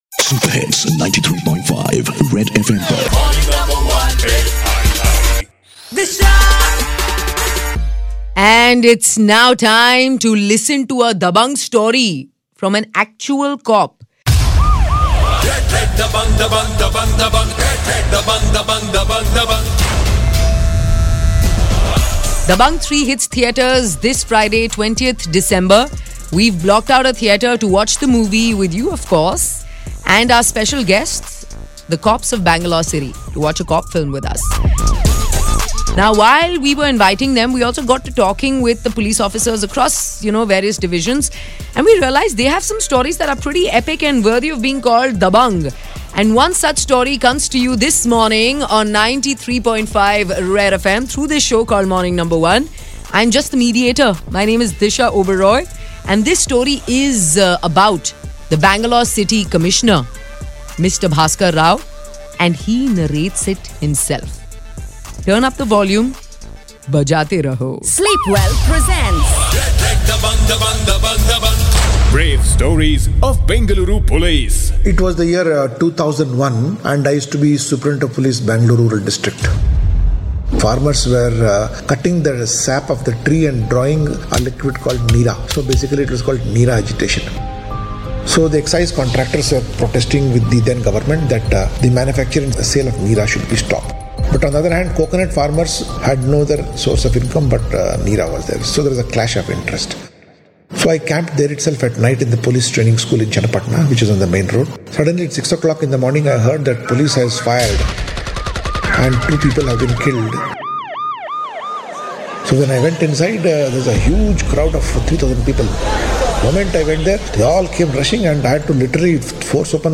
RED Dabangg is trending on Red Fm- Mr. Bhaskar Rao, Commissioner of Police, Bangalore City Police shares a chilling Dabangg story